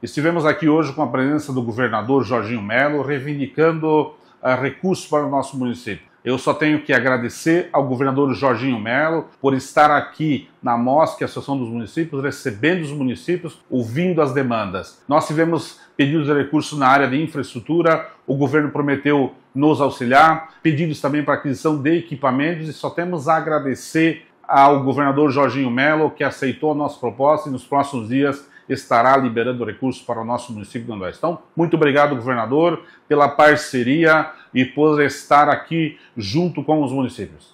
SONORA – SC Levada a Sério: prefeito de União do Oeste confirma recursos para obras de infraestrutura e equipamentos
Após a conversa individual com o governador Jorginho Mello, o prefeito de União do Oeste, Everaldo Luis Casonatto, fala sobre o encontro e adianta recursos garantidos para obras de infraestrutura e equipamentos: